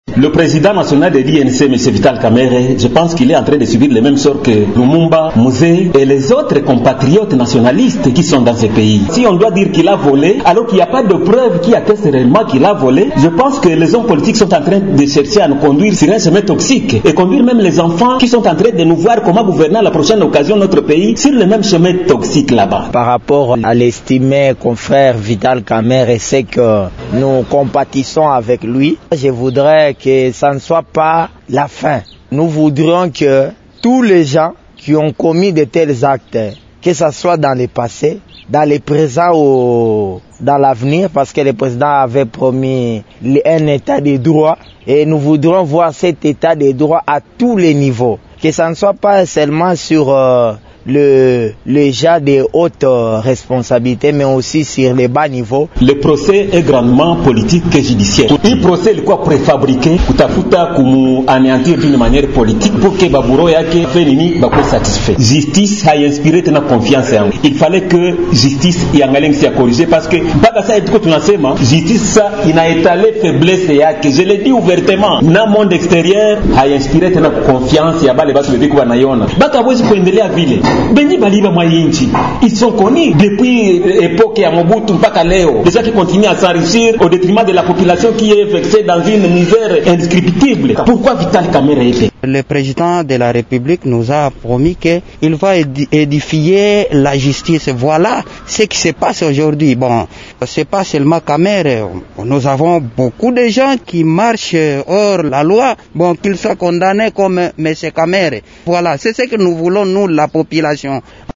kalemie_vox_pop_verdict_kamerhe_0.mp3